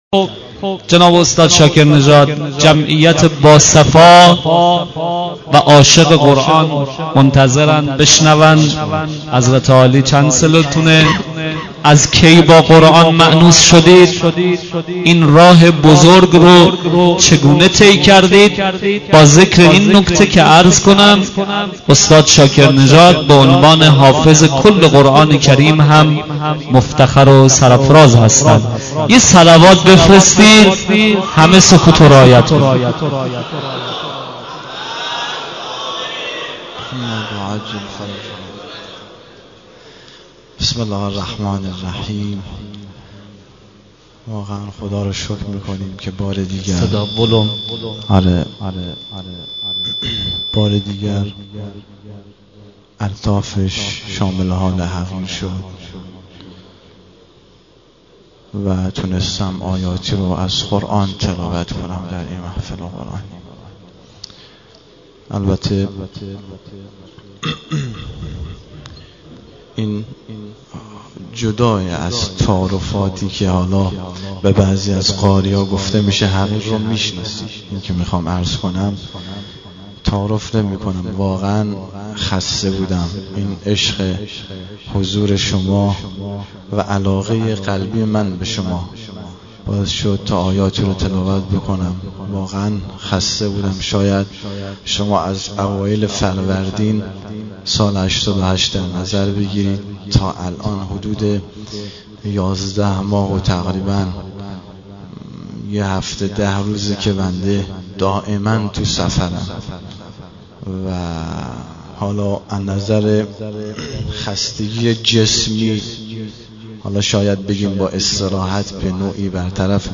مصاحبه حامدشاکرنژاد.mp3